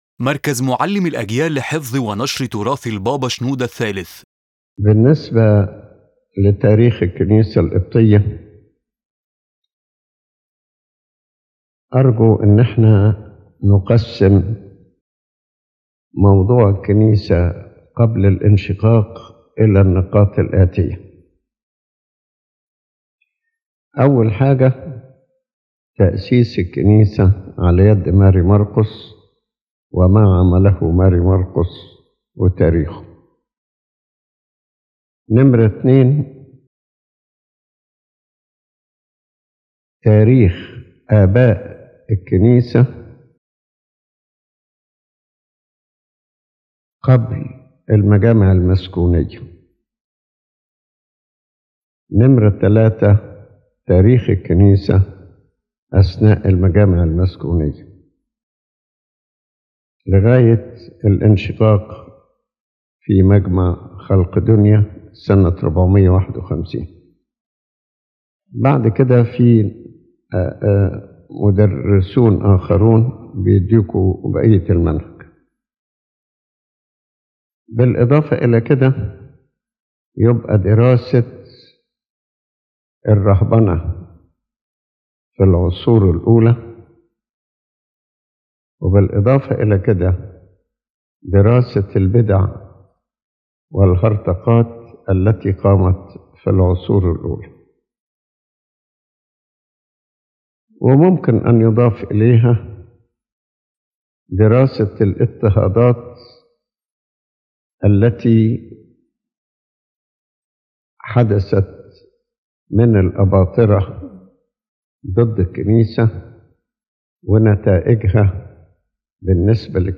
His Holiness Pope Shenouda III presents a structured historical study about the Coptic Orthodox Church before the schism at the Council of Chalcedon in 451 AD, explaining its foundation, teachings, fathers, theological schools, and its stance against heresies and persecutions.